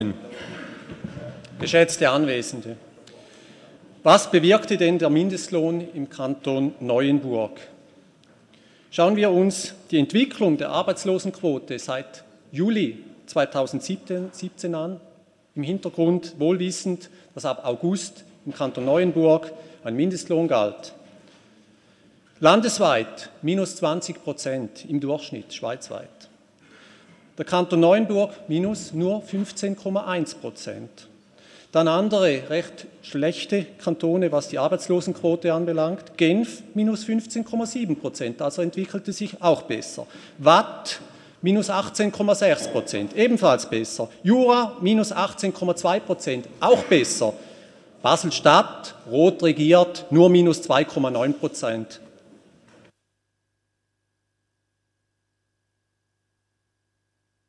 13.6.2018Wortmeldung
Session des Kantonsrates vom 11. bis 13. Juni 2018